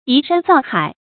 移山造海 yí shān zào hǎi
移山造海发音
成语注音ㄧˊ ㄕㄢ ㄗㄠˋ ㄏㄞˇ